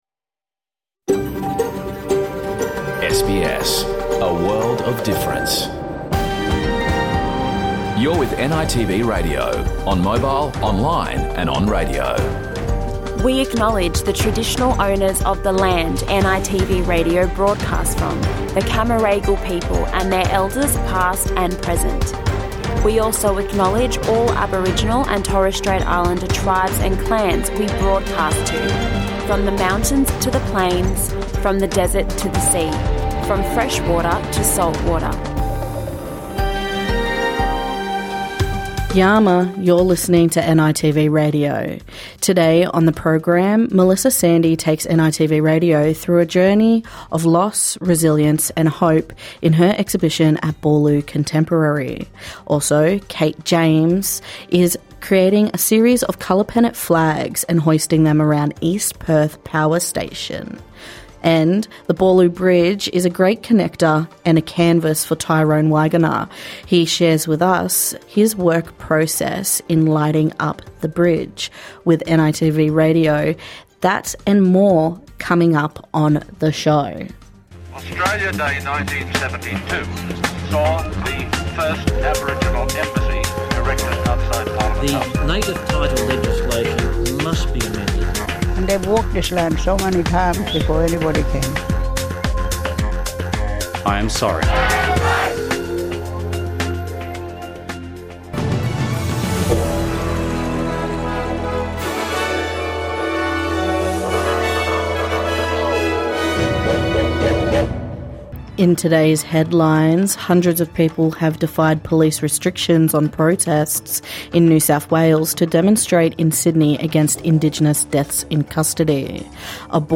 NITV Radio brings the latest in news, sport and weather as well as stories from across the country and in Community.